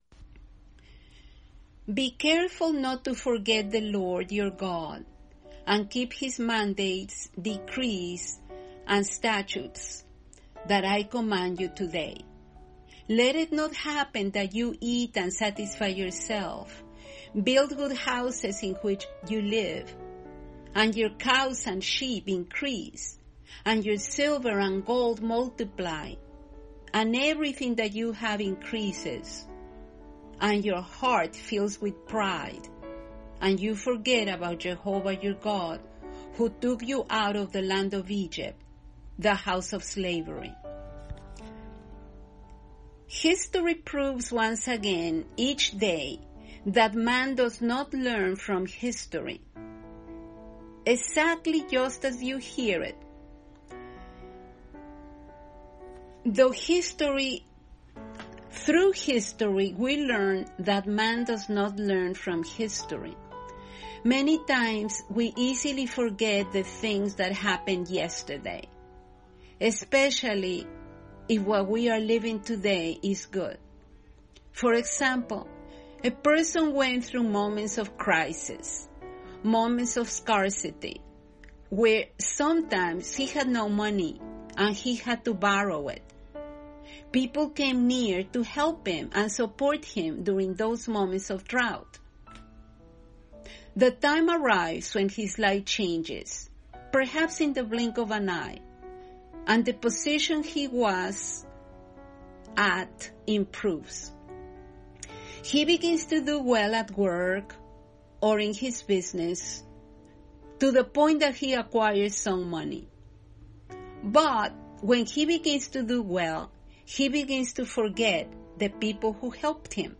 Devotional-Do-Not-Forget-Where-You-Came-From-.mp3